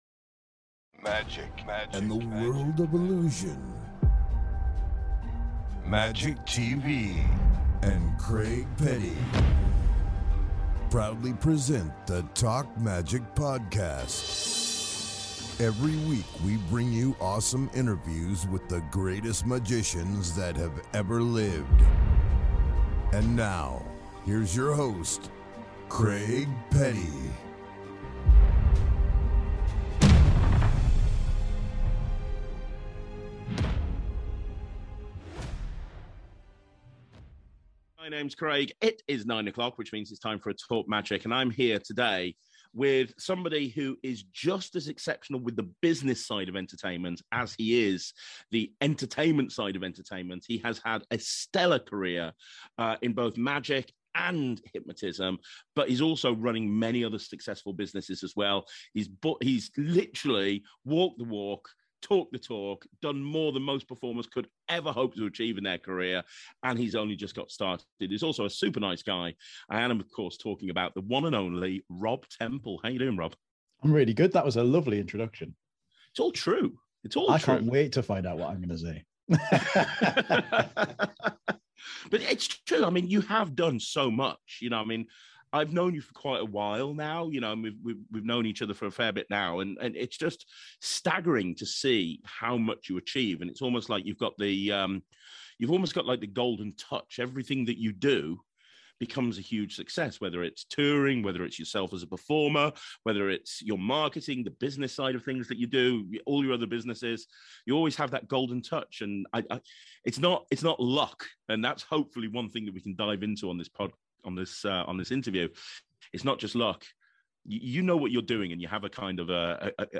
If you are a performer or have an interest in magic or hypnotism this is an interview you don't want to miss.